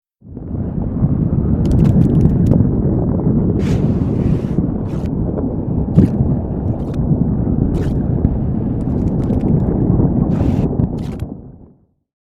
Mudslide 2
yt_wl3RnWSHQO8_mudslide_2.mp3